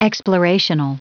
Prononciation du mot explorational en anglais (fichier audio)
Prononciation du mot : explorational